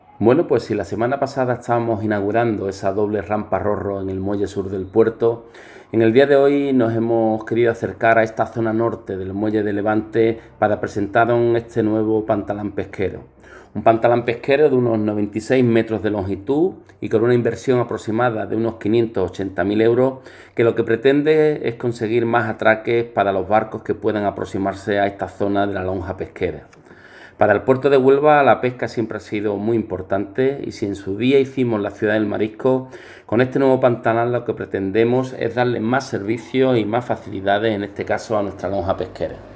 Alberto Santana, presidente del Puerto de Huelva 🔈
Audio-presidente-APH-Pantalan-Pesquero.m4a